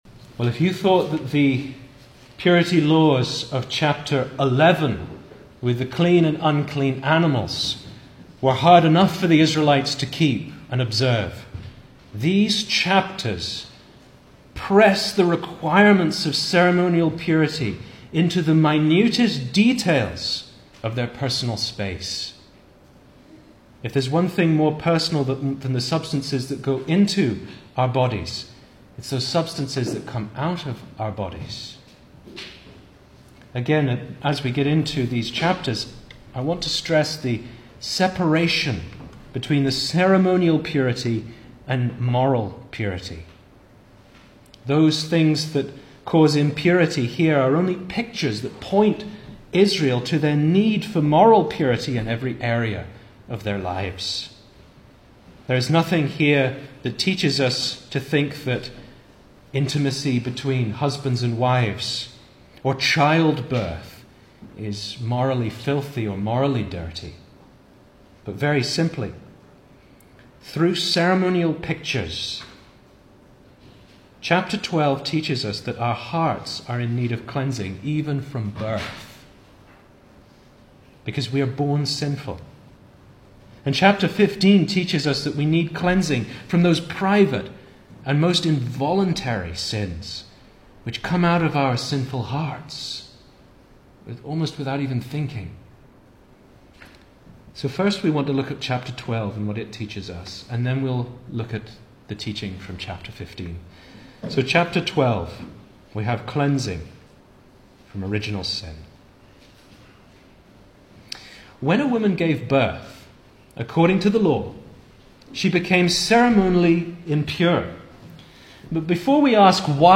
2024 Service Type: Sunday Evening Speaker